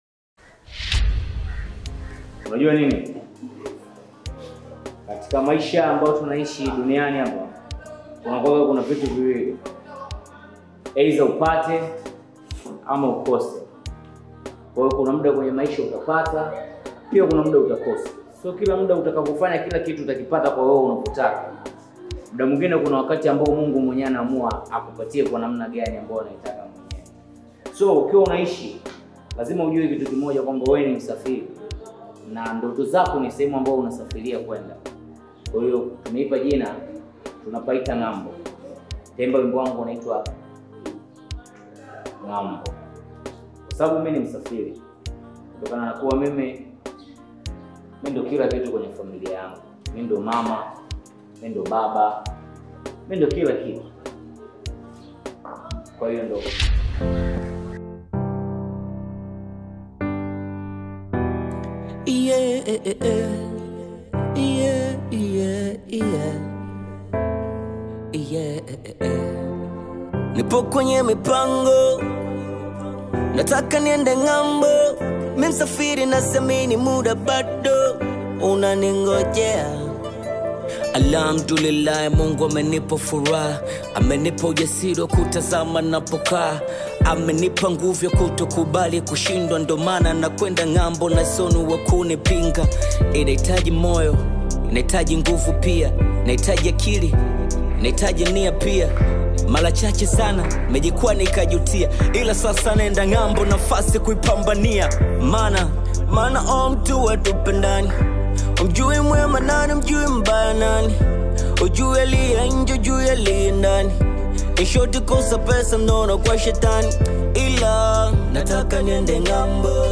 Bongo Flava and Hip Hop